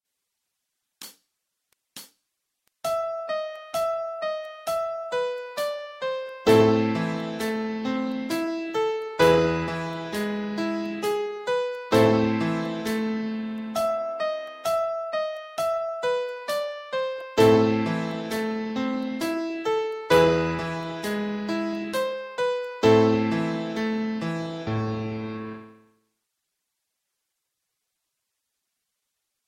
"Für Elise" melody track